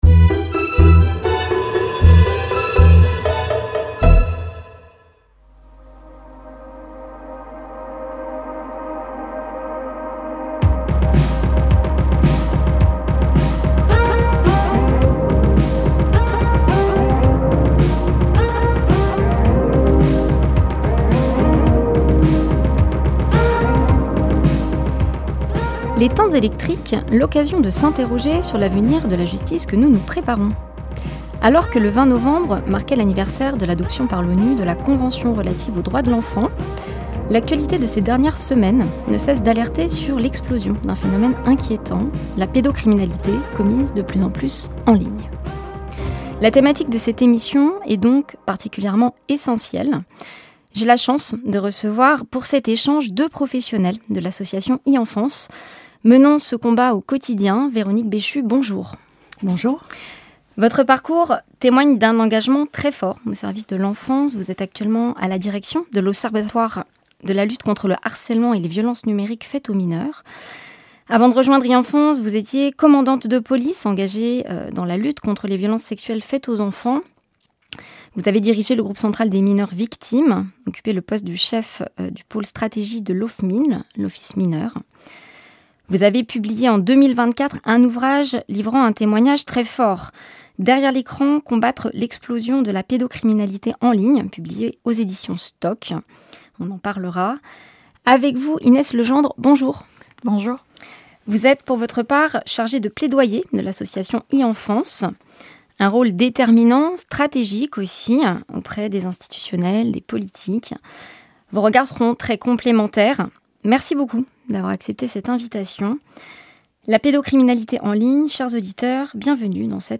Et pour redémarrer après la pause estivale, nous vous proposons une émission spéciale, en deux volets, sur le droit social et la protection sociale confrontés à l’essor des plateformes digitales. C’est tout d’abord dans les amphithéâtres de l’université Paris 1 Panthéon-Sorbonne